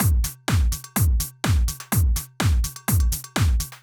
Drumloop 125bpm 07-A.wav